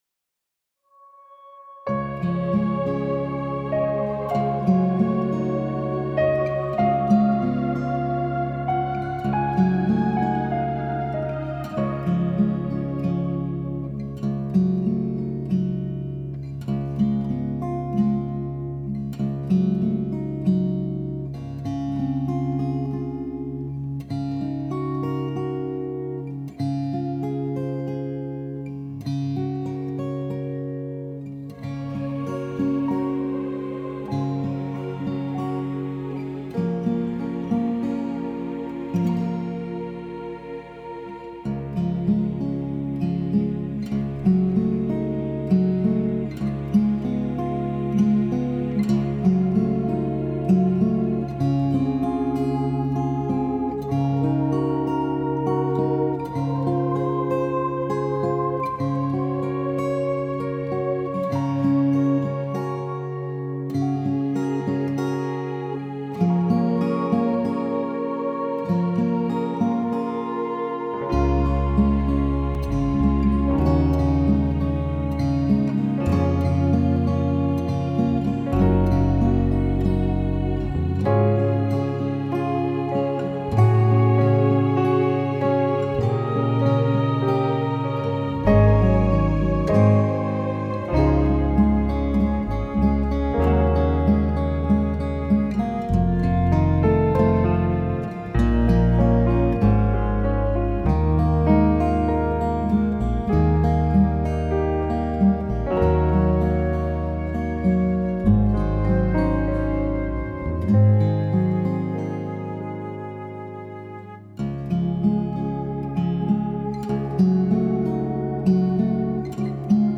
The new arrangements were magnificent – they were beautiful symphonies I enjoyed listening to while working on my paintings.
alabaster-karaoke-mix-5-8.mp3